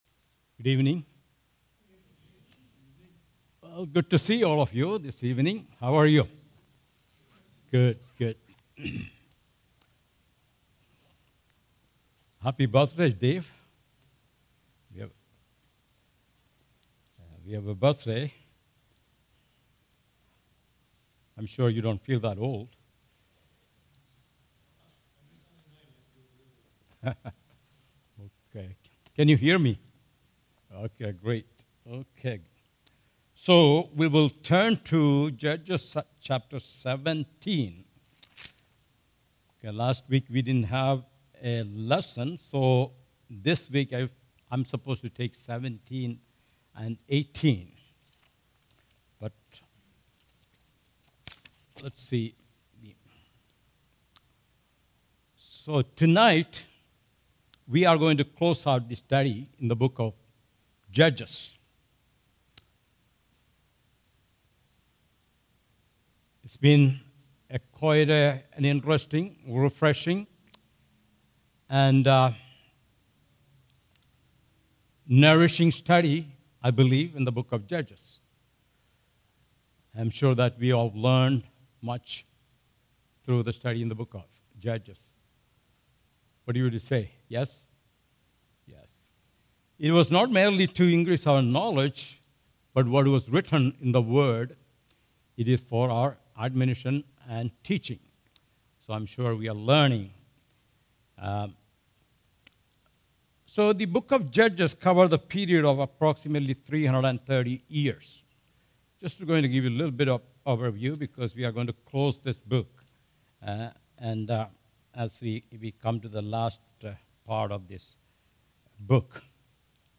All Sermons Judges 17 | What’s Wrong With This Picture?